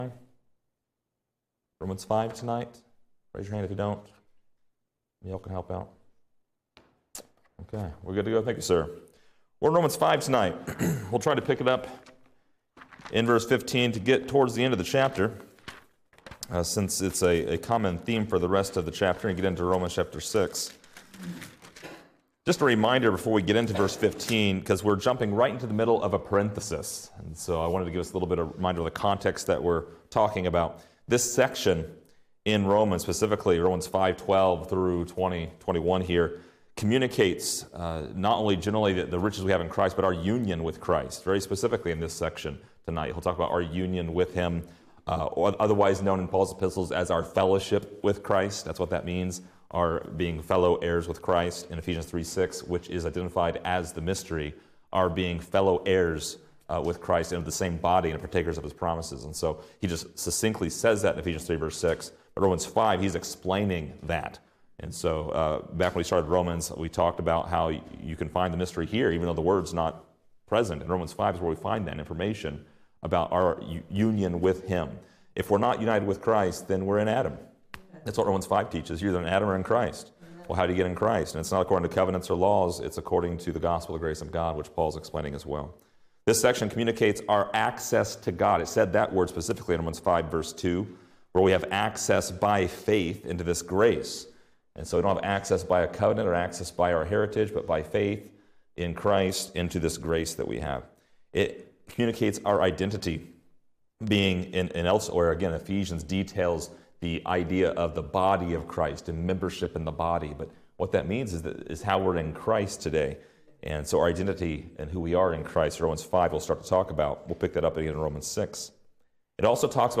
Description: This lesson is part 36 in a verse by verse study through Romans titled: By One Man.